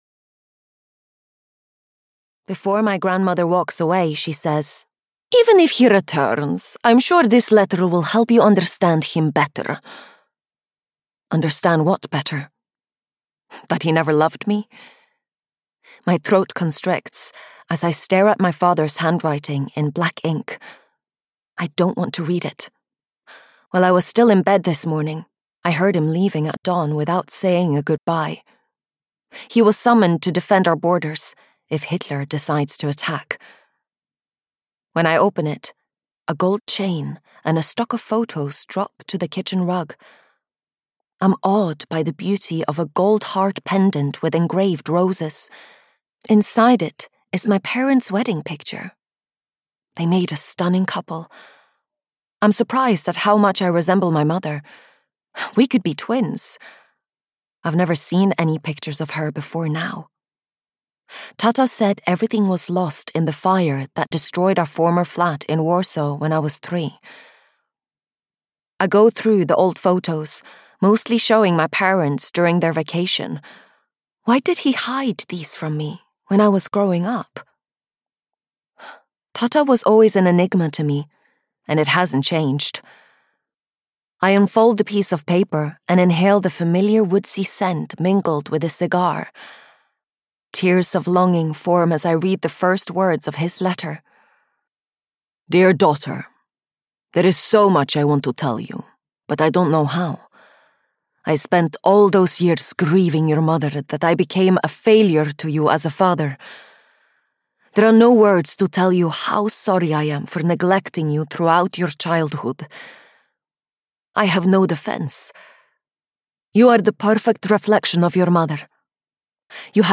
Daughter of the Resistance (EN) audiokniha
Ukázka z knihy